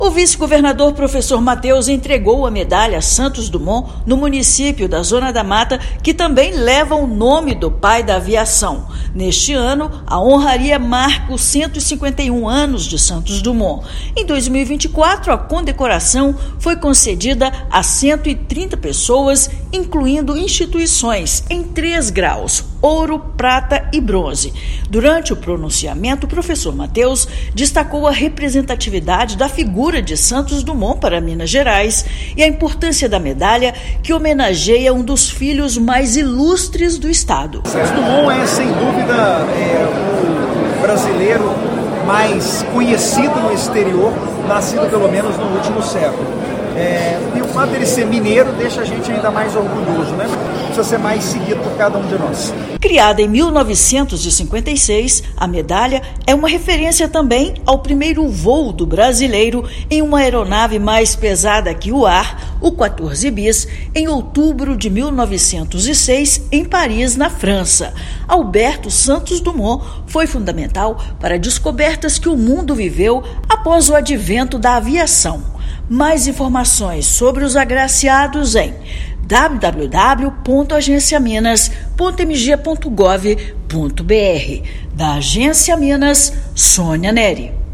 Cerimônia faz referência aos 151 anos de nascimento do inventor mineiro, com 130 agraciados em 2024. Ouça matéria de rádio.